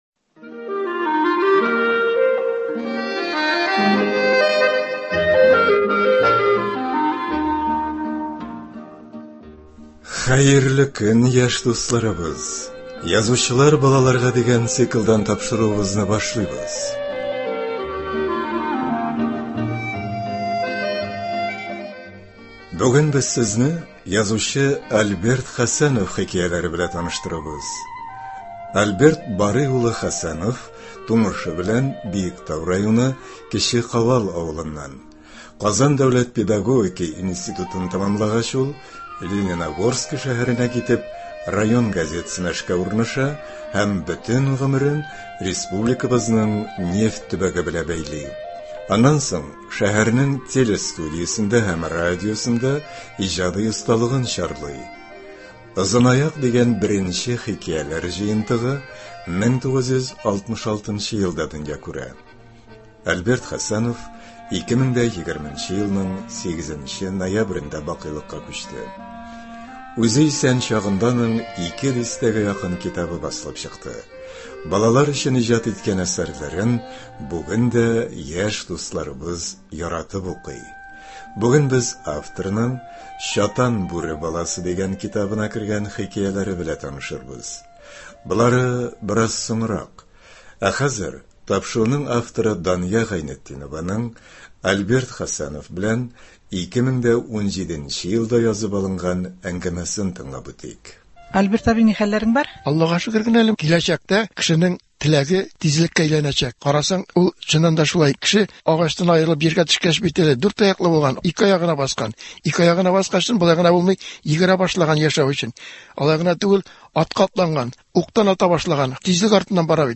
тапшыру барышында автор белән 2017 елда язып алынган әңгәмә дә ишетербез.